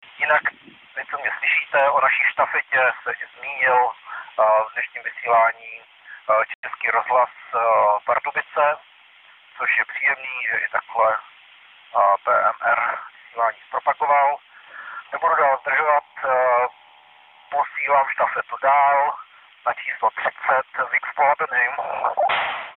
Šumění narušil vstup relace posádky jednatřicet!